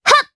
Erze-Vox_Attack1_jp.wav